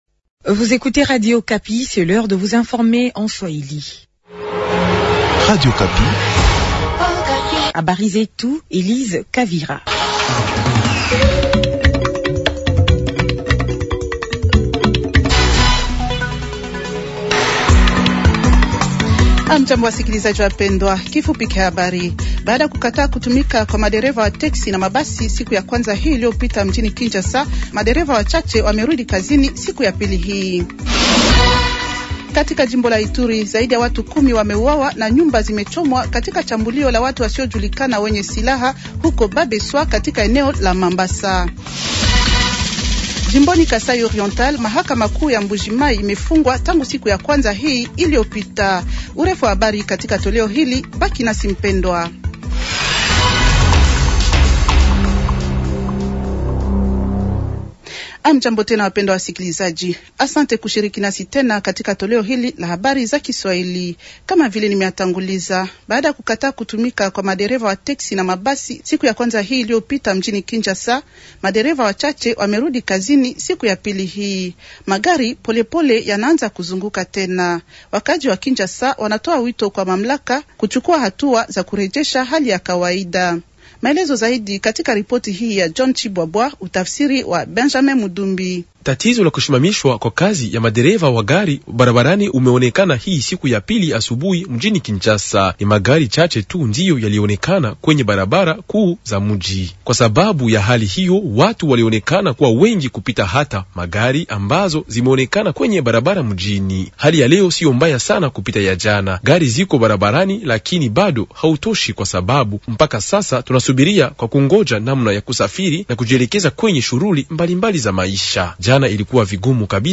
Journal Swahili de mardi soir 170326
Habari za mangaribi siku ya pili 170326